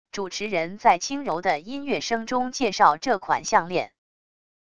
主持人在轻柔的音乐声中介绍这款项链wav音频